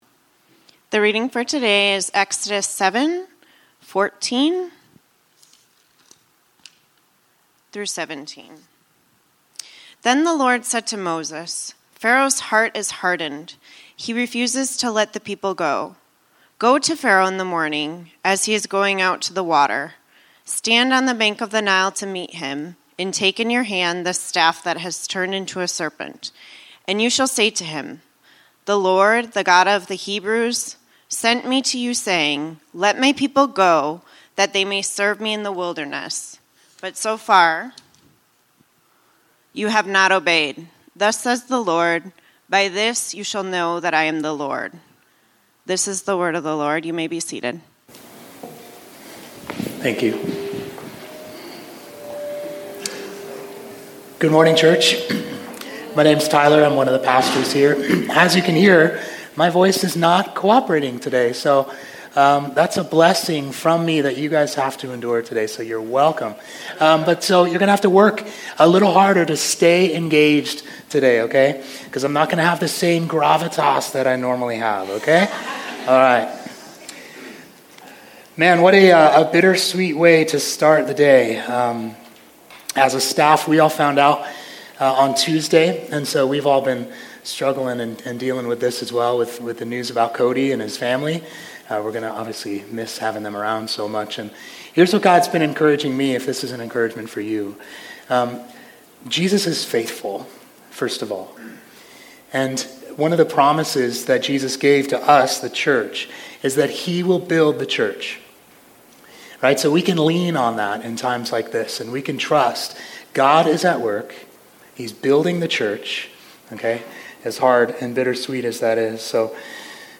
Exodus: The Plagues (Redemption Arcadia Sermons)